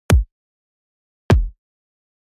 こちらのふたつのキックの音色を比較すると、2個目の方は少しだけ音の立ち上がりに鈍さがあります。
1個目のキック:即座にピーク
2個目のキック:わずかにピークが遅い
P-ENV-A-Kicks.mp3